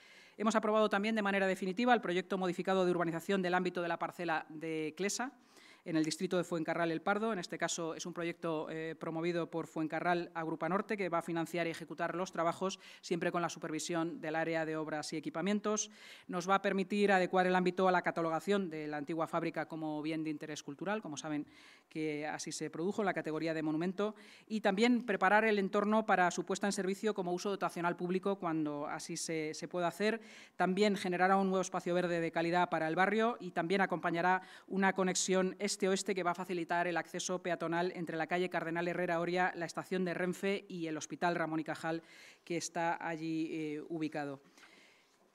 Nueva ventana:La vicealcaldesa y portavoz municipal, Inma Sanz